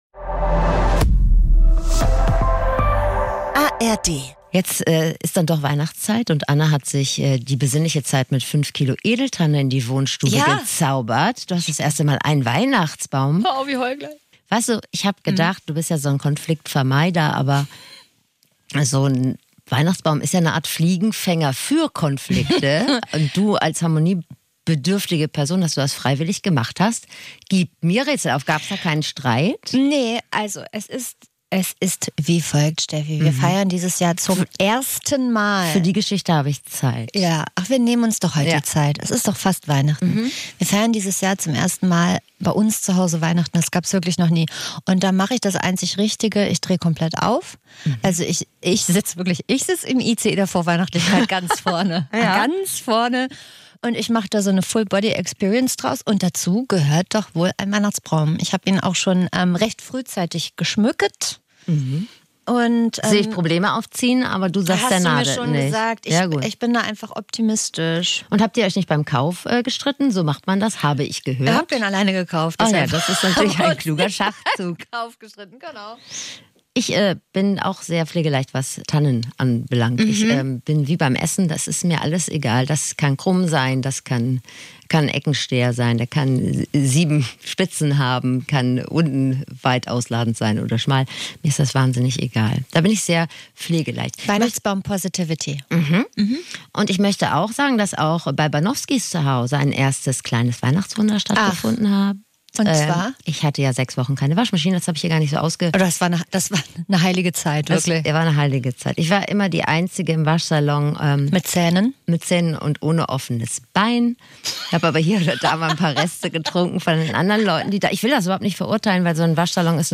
Dieser Laber-Podcast mit Bildungsauftrag versorgt Euch mit Klugscheißerwissen, mit dem Ihr ordentlich flexen könnt.